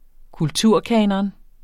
Udtale [ -ˌkæːnʌn ]